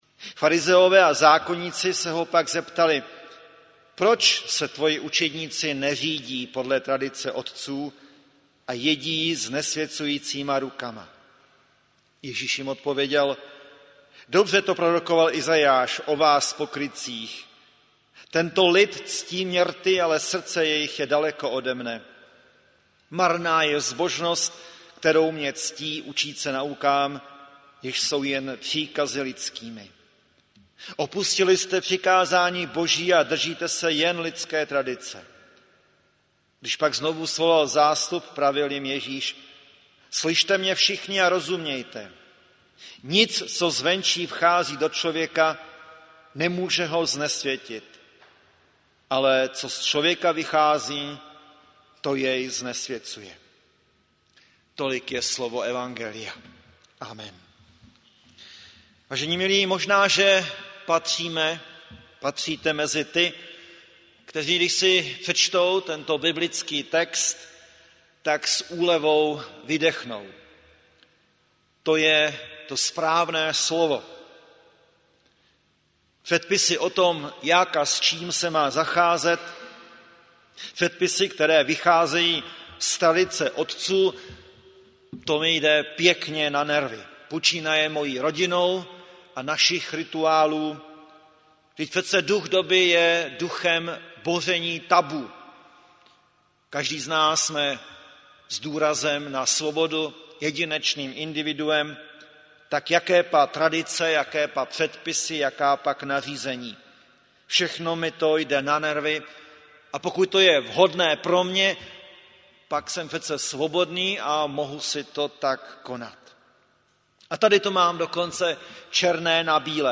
Záznam kázání a fotografie z hudebních nešpor ve znamení kapely OBOROH.
Po krátké odmlce, z důvodu letních prázdnin, pokračují tradiční hudební nešpory.